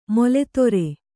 ♪ mole tore